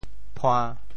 番禺 潮语发音 展开其他区域 潮州 puan1 ngo5 潮州 0 1 中文解释 番禺 更多 タグ: 地名 词条状态: 贡献者提交 《潮典》测试版提示您： 词条处在改进状态中，如您发现错误，请及时与我们联系。